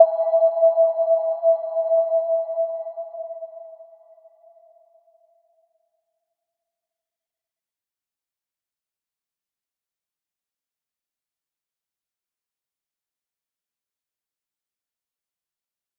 Little-Pluck-E5-mf.wav